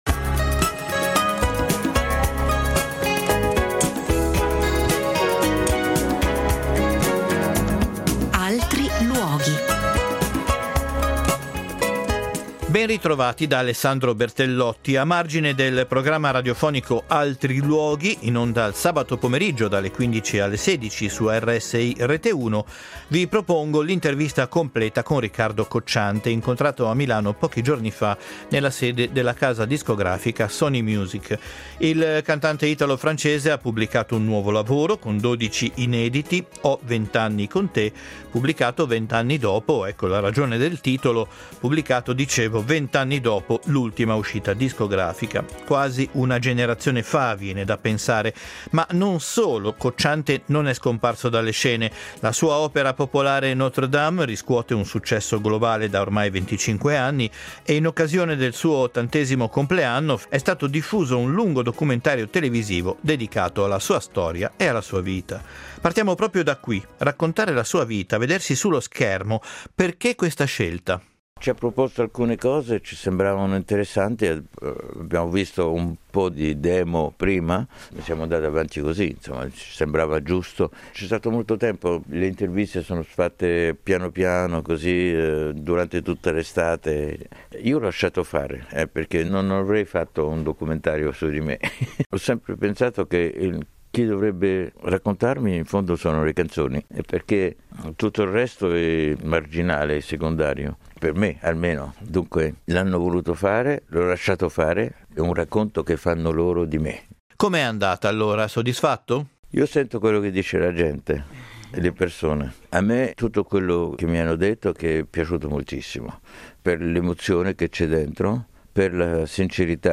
Intervista integrale a Riccardo Cocciante